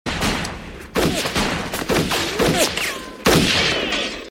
• GUNSHOTS NEARBY.mp3
gunshots_nearby_w2c.wav